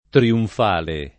triunfale [ triunf # le ]